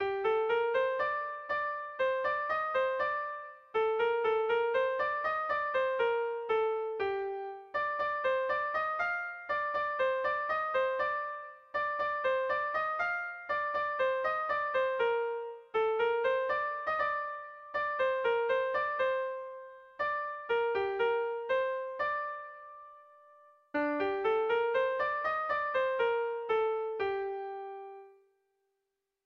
Irrizkoa
Hamahirukoa, txikiaren moldekoa, 7 puntuz (hg) / Zazpi puntukoa, txikiaren moldekoa (ip)
6 / 5A / 7 / 5A / 7 / 6A / 7 / 7A / 6 / 6A / 6A / 7 / 5A (hg) | 11A / 12A / 13A / 14A / 12A / 6A / 12A (ip)